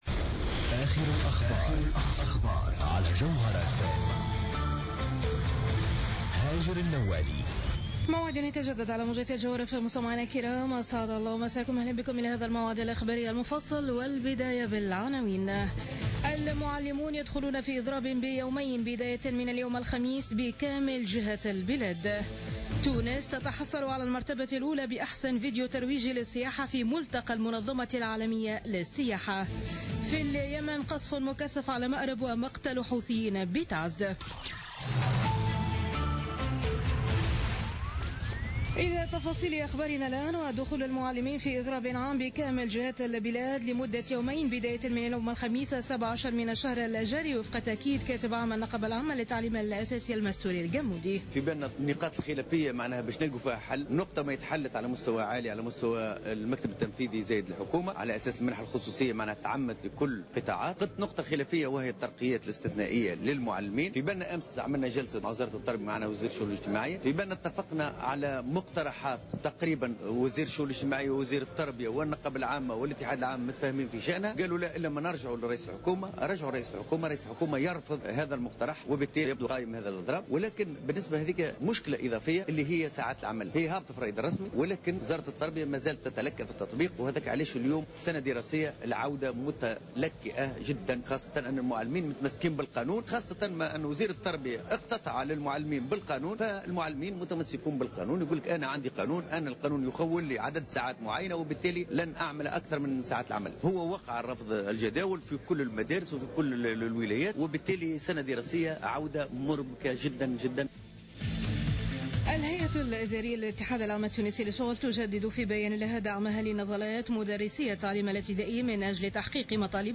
نشرة أخبار منتصف الليل ليوم الخميس 17 سبتمبر 2015